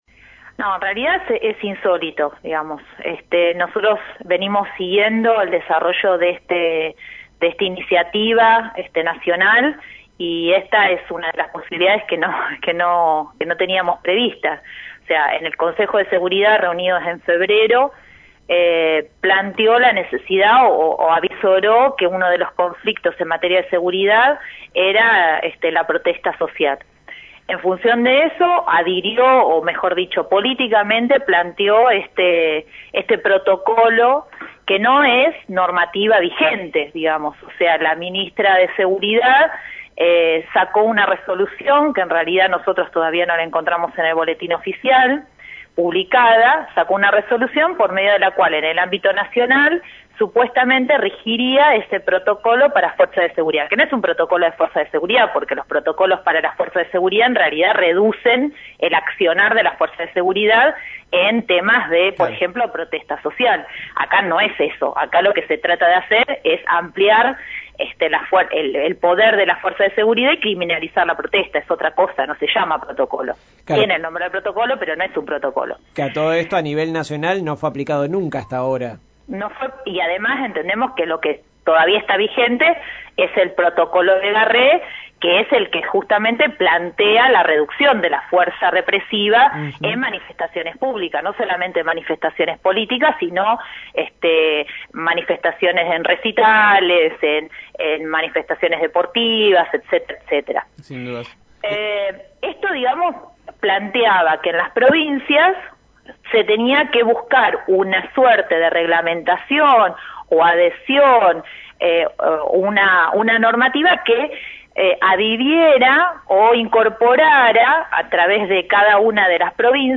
Al respecto Caídos del Catre conversó con la abogada